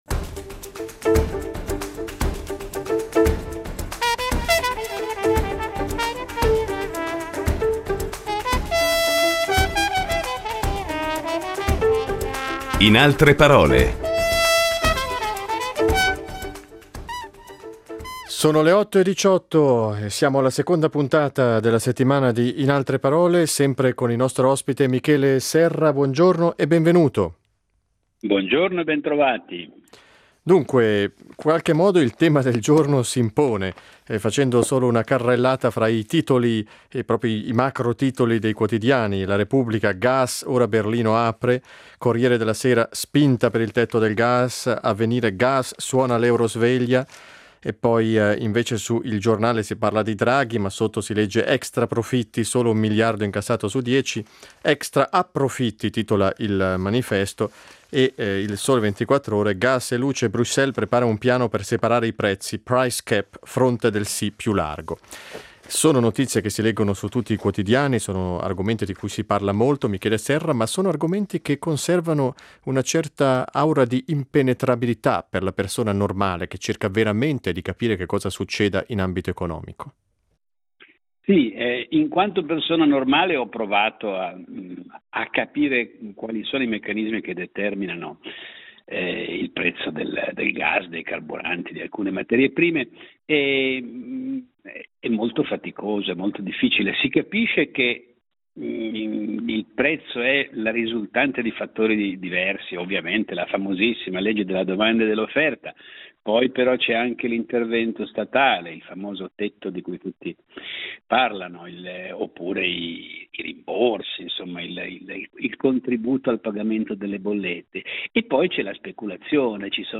Michele Serra dialogherà